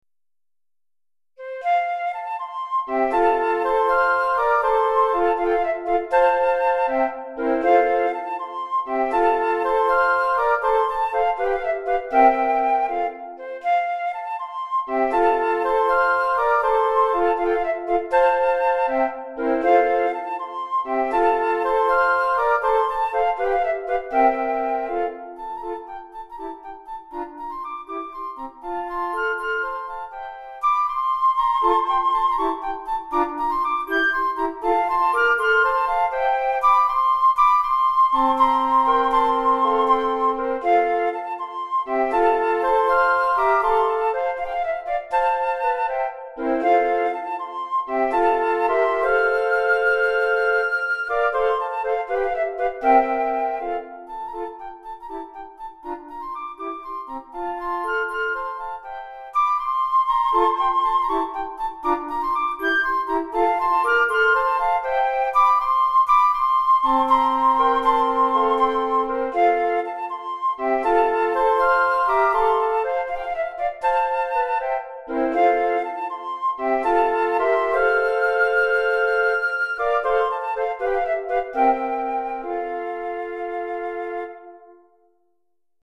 4 Flûtes Traversières